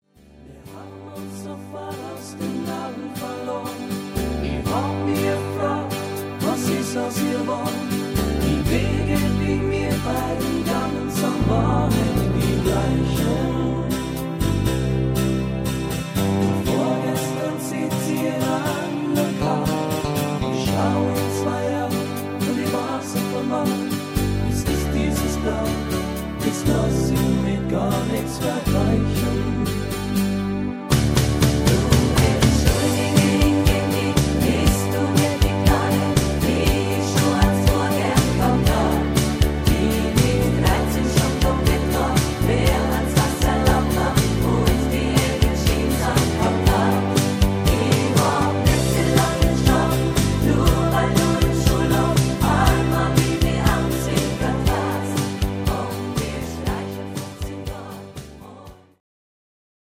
Rhythmus  Medium Beat
Art  Schlager 90er, Deutsch